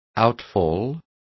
Complete with pronunciation of the translation of outfall.